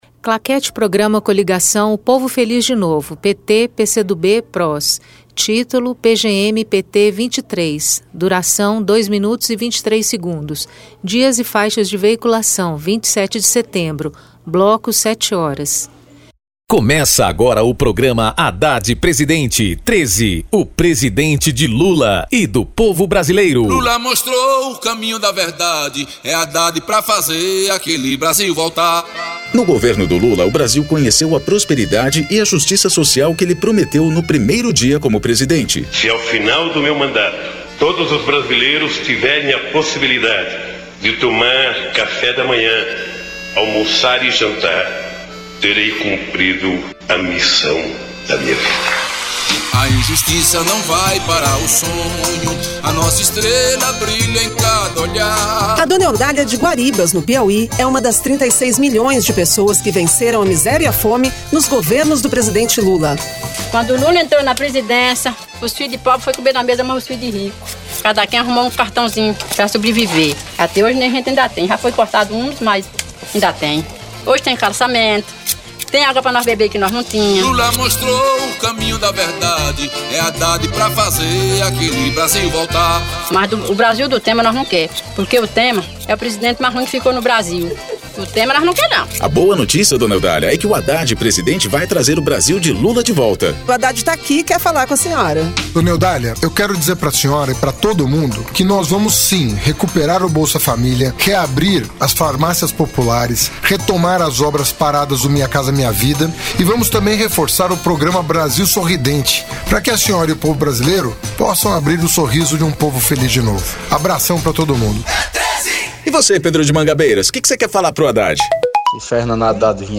TítuloPrograma de rádio da campanha de 2018 (edição 23)
Descrição Programa de rádio da campanha de 2018 (edição 23) - 1° turno